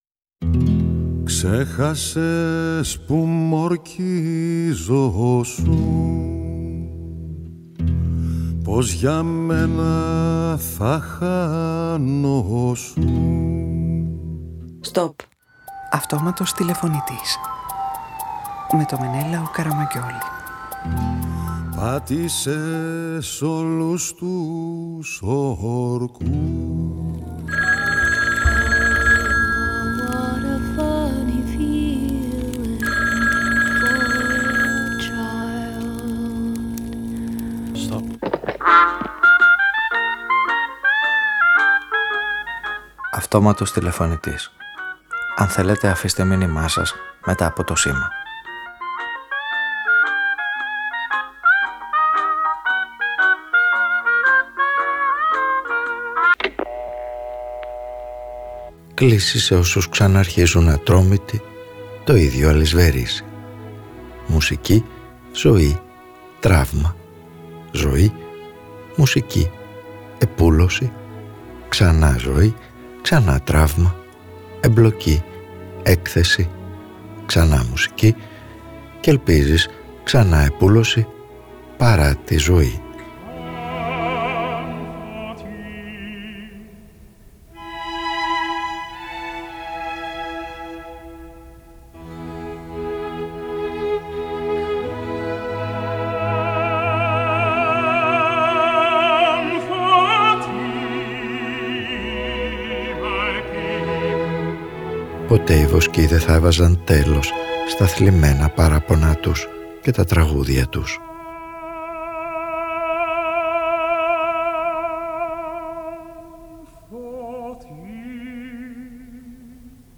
ΕΝΑΣ ΠΟΥ ΦΟΒΗΘΗΚΕ ΝΑ ΓΙΝΕΙ ΔΥΟ ΜΟΥΣΙΚΗ, ΖΩΗ, ΤΡΑΥΜΑ, ΖΩΗ, ΜΟΥΣΙΚΗ, ΕΠΟΥΛΩΣΗ, ΞΑΝΑ ΖΩΗ, ΞΑΝΑ ΤΡΑΥΜΑ, ΕΜΠΛΟΚΗ, ΕΚΘΕΣΗ, ΞΑΝΑ ΜΟΥΣΙΚΗ Ο ήρωας της σημερινής ραδιοφωνικής ταινίας καταφεύγει στη μουσική ως ένα δοκιμασμένο τρόπο επούλωσης τραυμάτων, επαναπροσδιορισμού και επαναδιεκδίκησης της ζωής.